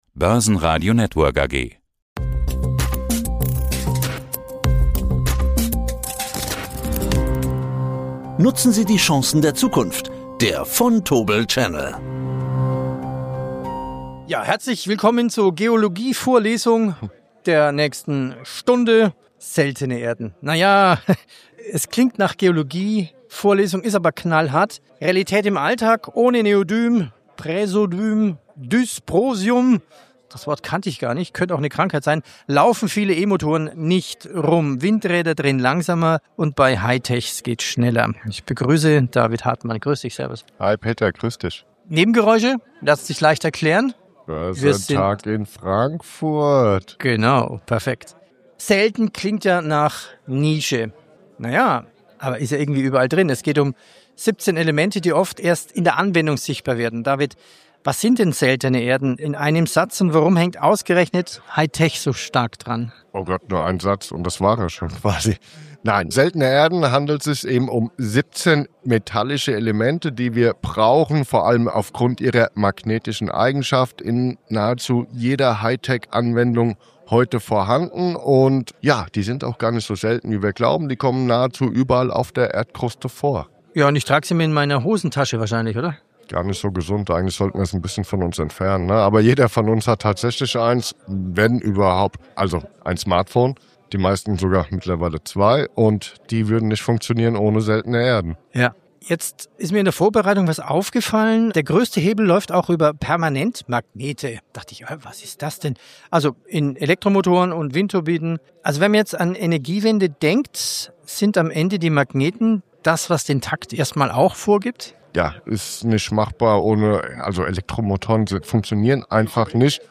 auf dem Börsentag in Frankfurt.